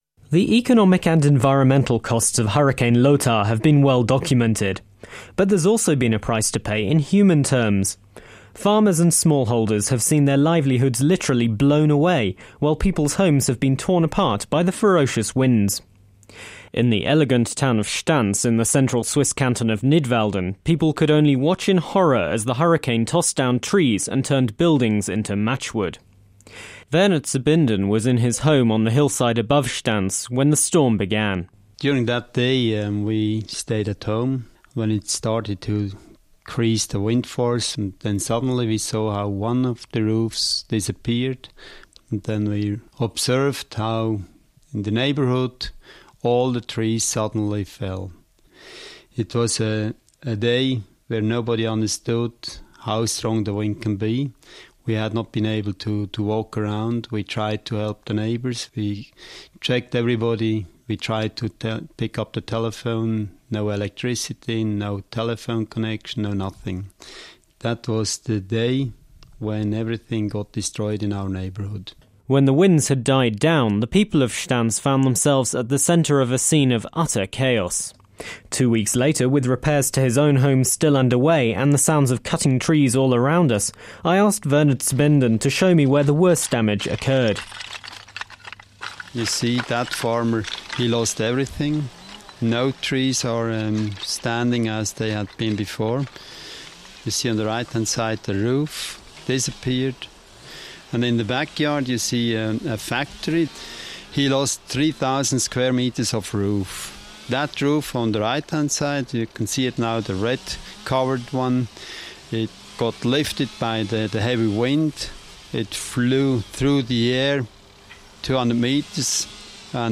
Swiss Radio International journalist
reports.